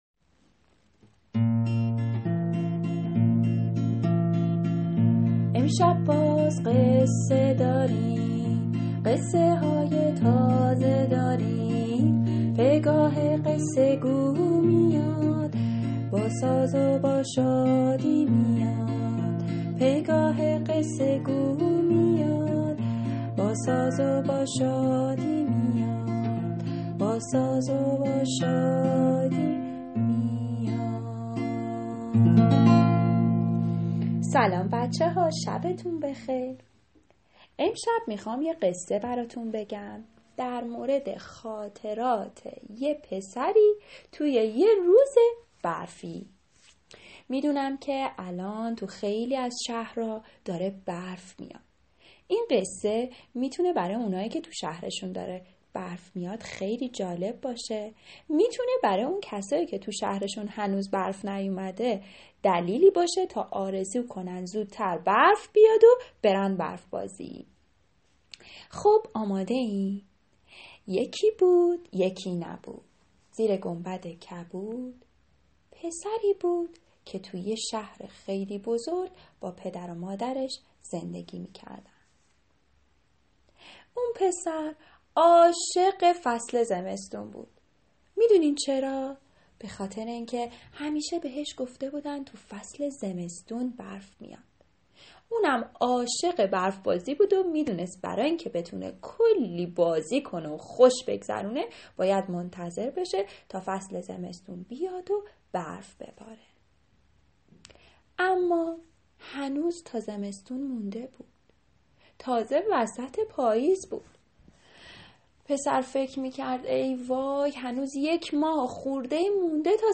قصه کودکانه صوتی برف و شادی